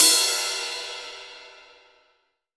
• Crash Cymbal Sound E Key 01.wav
Royality free crash cymbal drum sample tuned to the E note. Loudest frequency: 7731Hz
crash-cymbal-sound-e-key-01-Lwg.wav